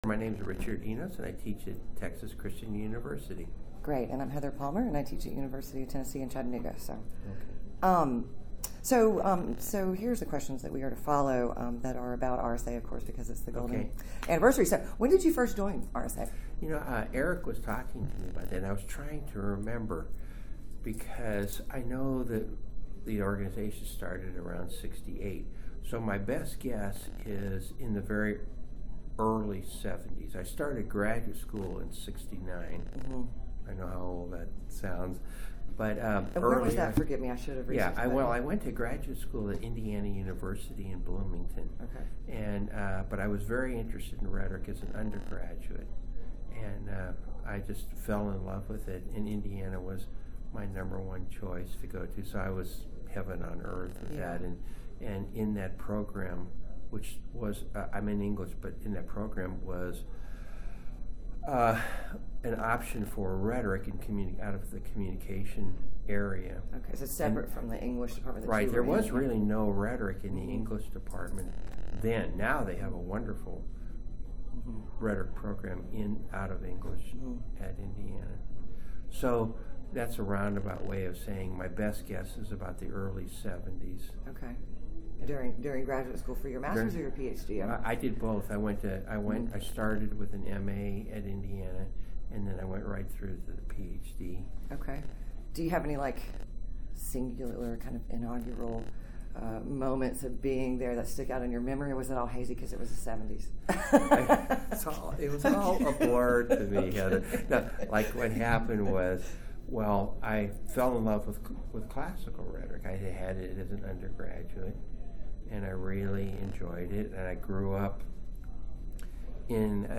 Type Oral History
Location 2018 RSA Conference in Minneapolis, Minnesota